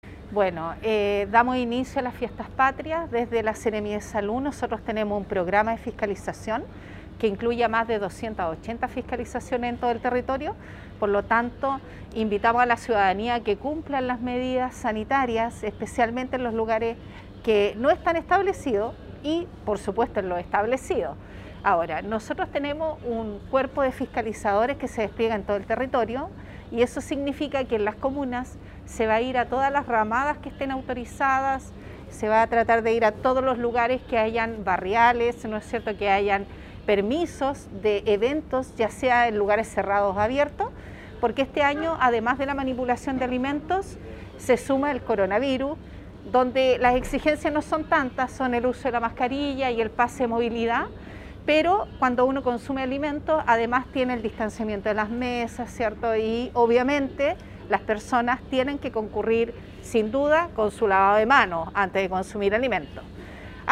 AUDIO :Seremi de Salud Paola Salas